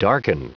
Prononciation du mot darken en anglais (fichier audio)
Prononciation du mot : darken